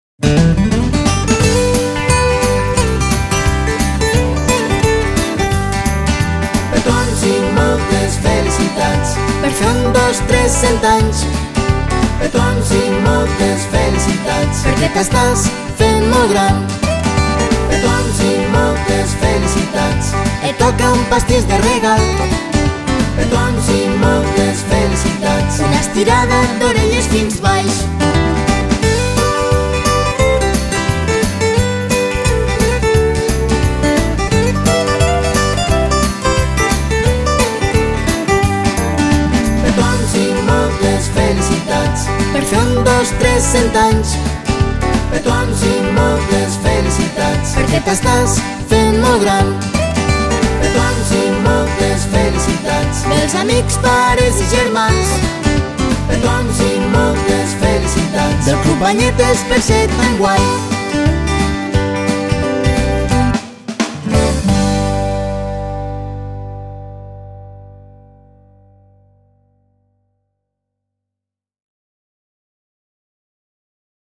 03-petons-i-moltes-felicitats-cantat.mp3